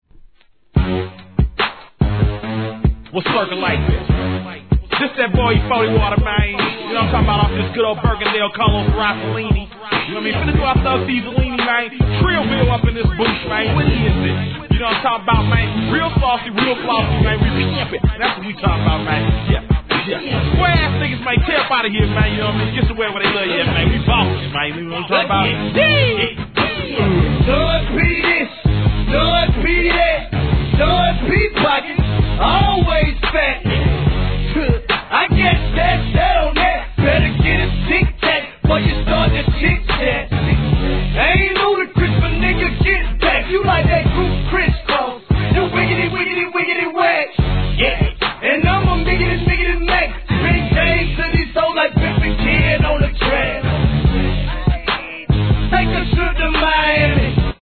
HIP HOP/R&B
ダウナーSOUTHビートで「ディギリディギリ!」炸裂MICリレー!!